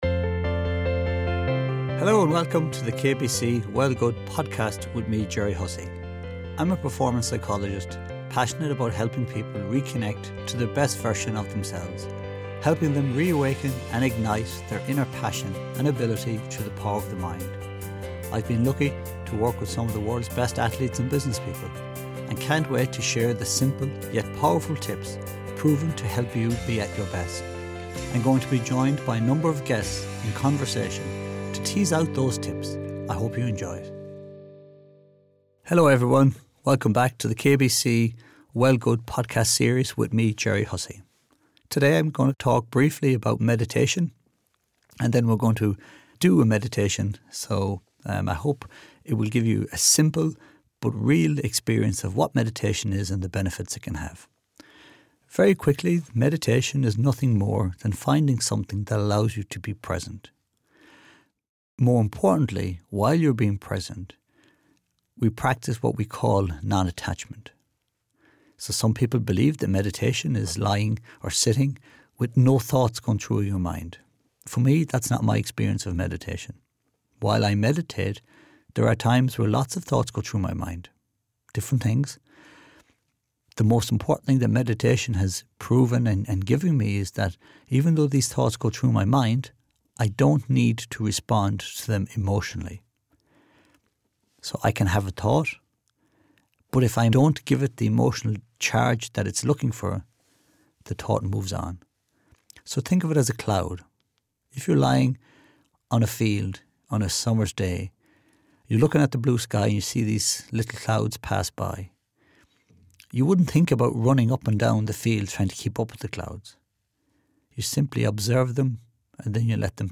He then takes us through a simple 10 minute guided meditation which can be done at any point in the day. You could even tune into this before a big meeting, presentation or interview to help you be present and at your best.